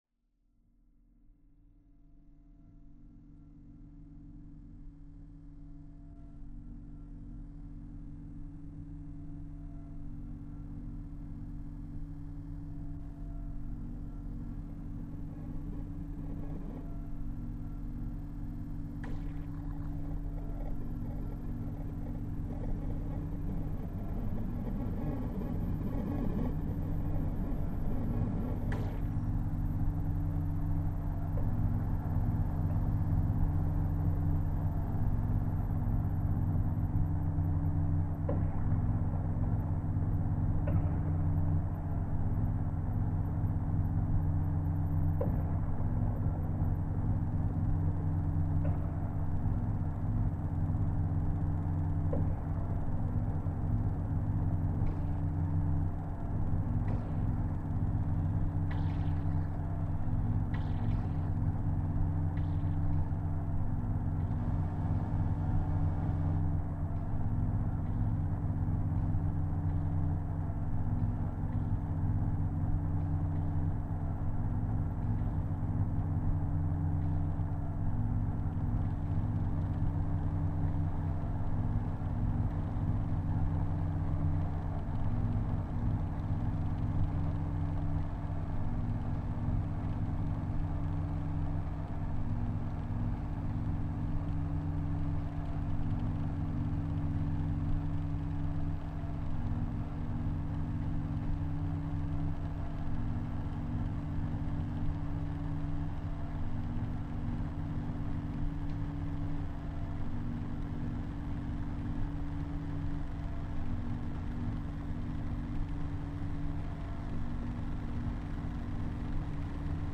drummer
electronics
the cracked everyday electronics